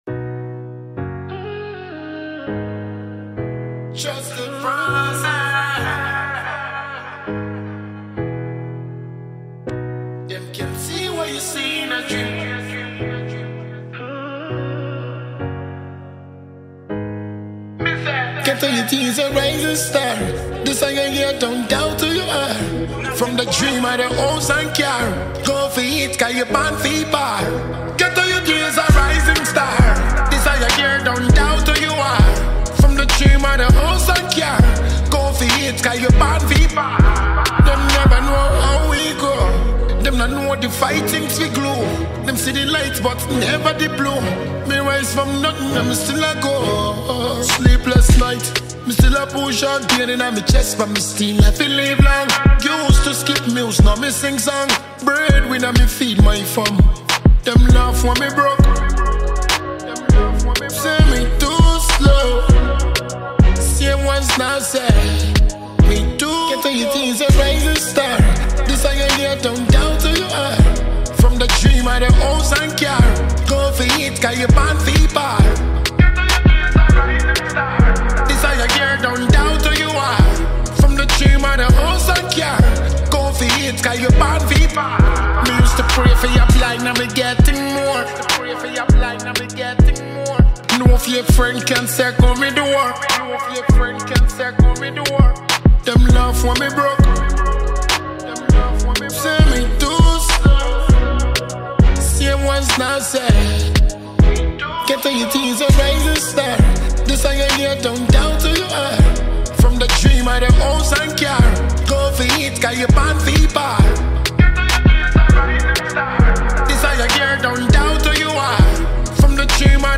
confident and energetic single